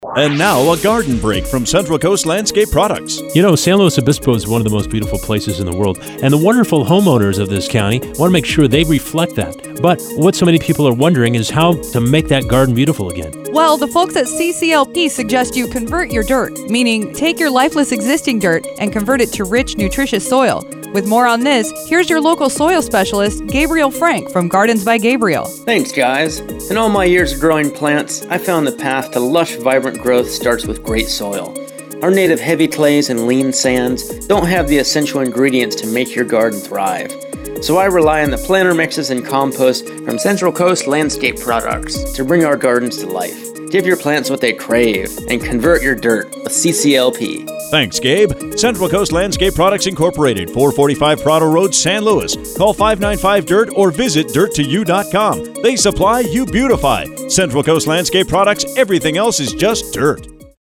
They've recently begun a radio campaign and invited us to put our spin on their products.